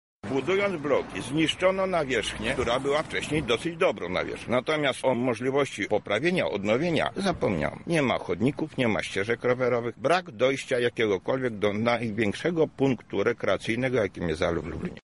Właściciel posesji